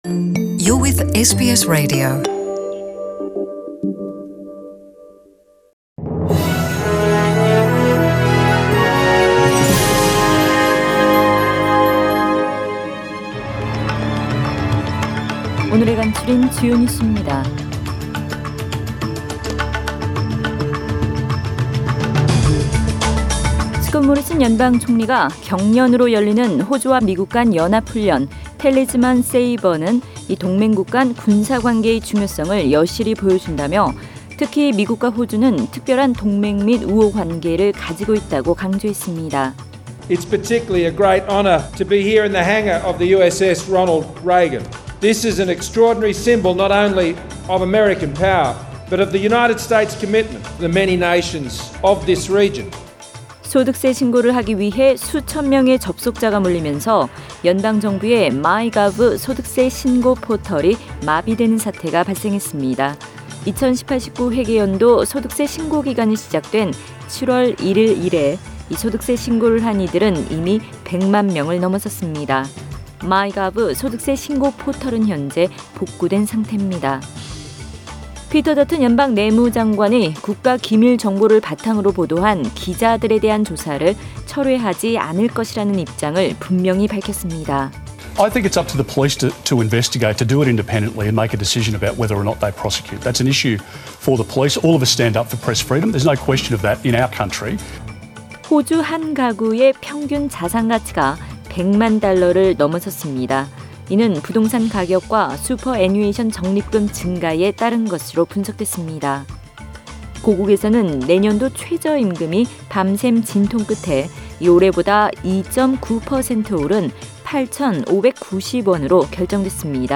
SBS 한국어 뉴스 간추린 주요 소식 – 7월 12일 금요일